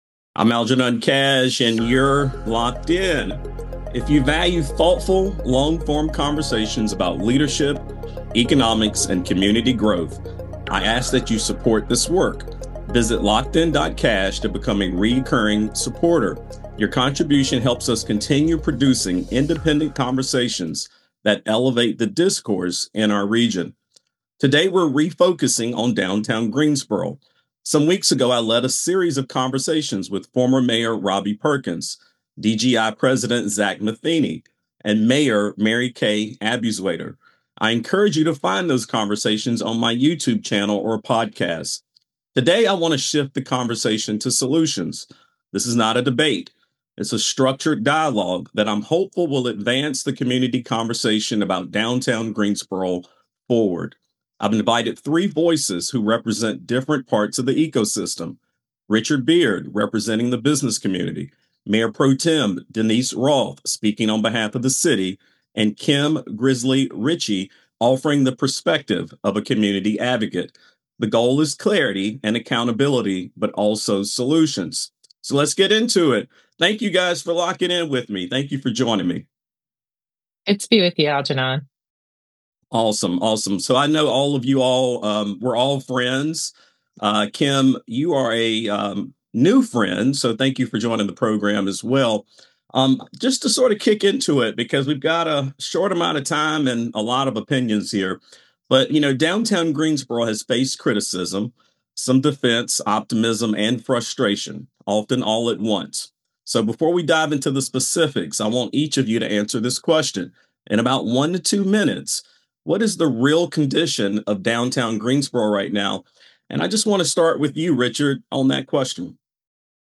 candid panel discussion on the future of downtown Greensboro